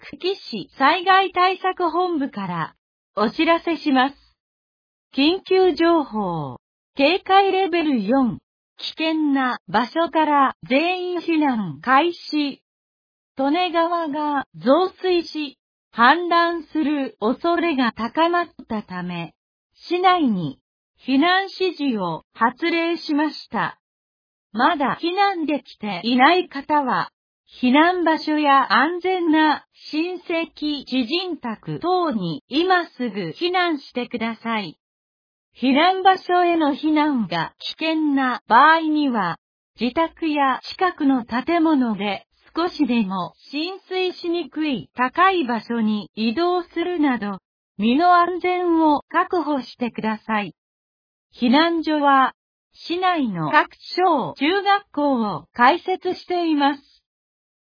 行方不明者および行方不明者保護放送、振り込め詐欺被害防止放送、火災の発生・鎮火、光化学スモッグ注意報・警報、熱中症警戒アラート発令、Jアラート関係の放送など 架電・配信内容 避難情報発令時は、災害対策本部から登録者の固定電話へ一斉に架電を行います。